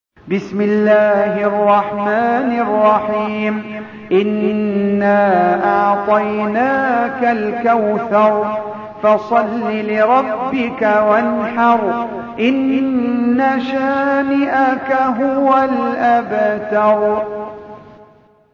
Surah Sequence تتابع السورة Download Surah حمّل السورة Reciting Murattalah Audio for 108. Surah Al-Kauthar سورة الكوثر N.B *Surah Includes Al-Basmalah Reciters Sequents تتابع التلاوات Reciters Repeats تكرار التلاوات